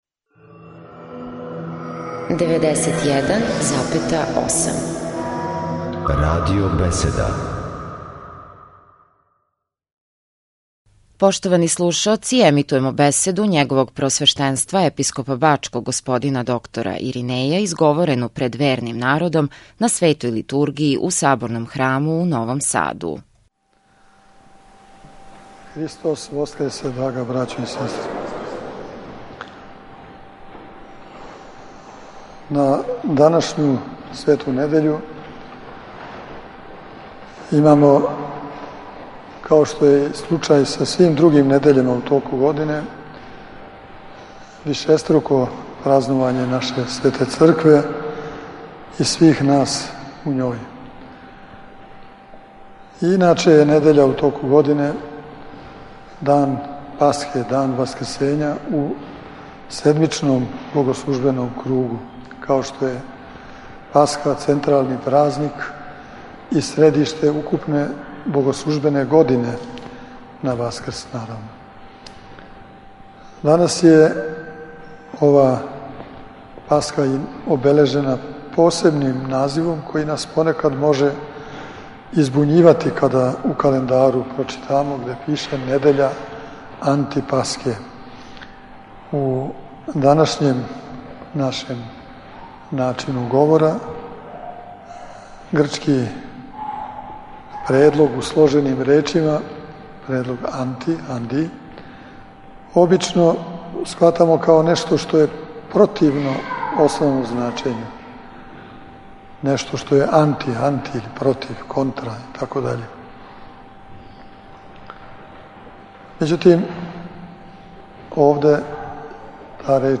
У Томину недељу или недељу Антипасхе, у Светогеоргијевском Саборном храму у Новом Саду свету архијерејску Литургију служио је Његово Преосвештенство Епископ бачки Господин Иринеј, уз саслужење братства овог храма и новосадских ђаконâ.